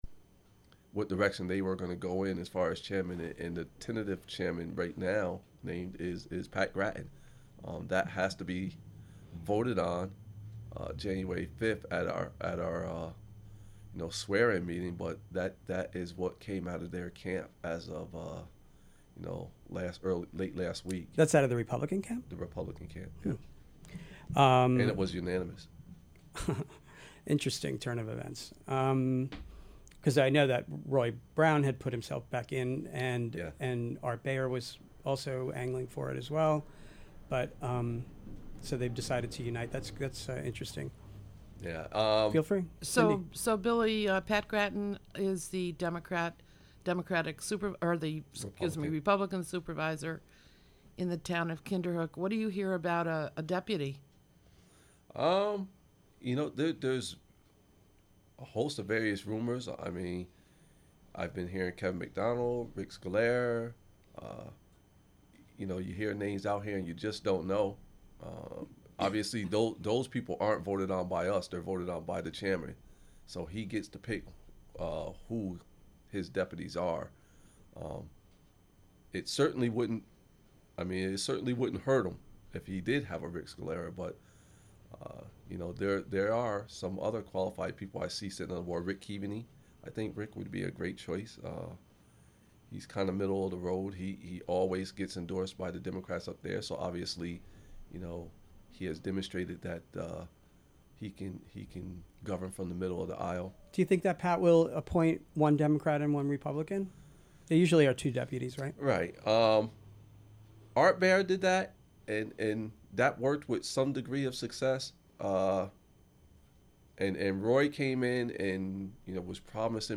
First part of show is missing from recording.